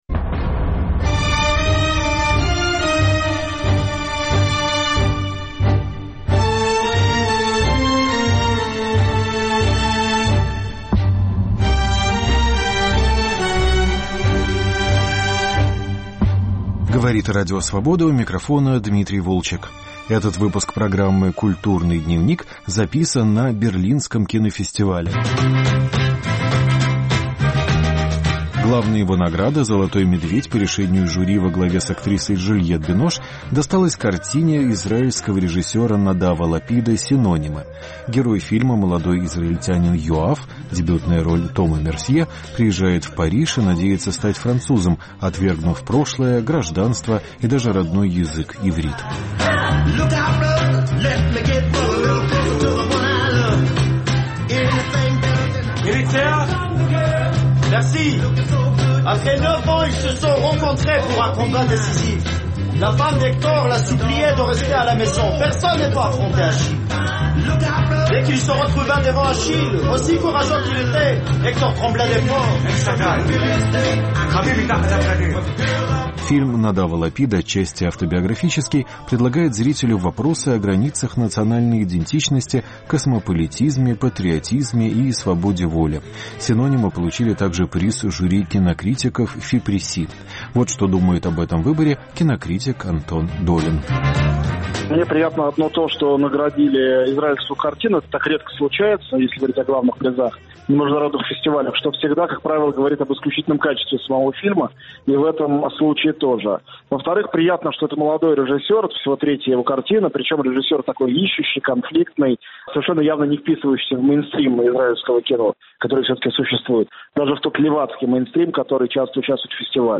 Разговоры на Берлинском кинофестивале